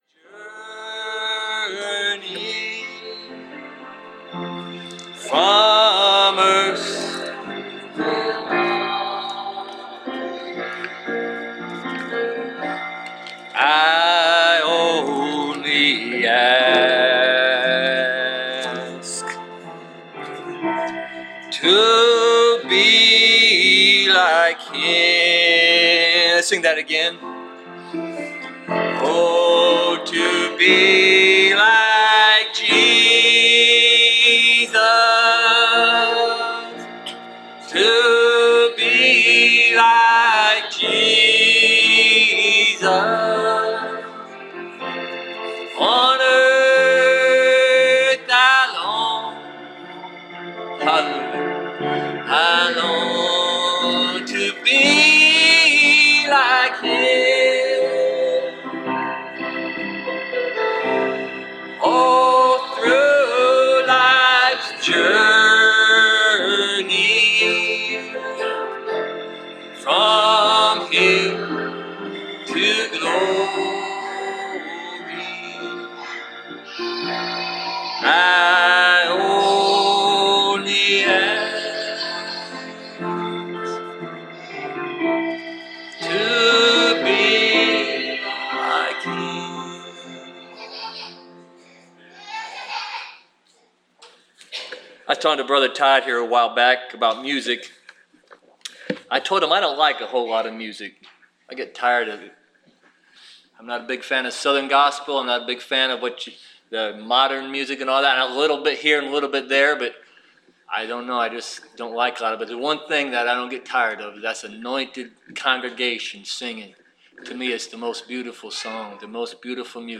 Preached July 20, 2017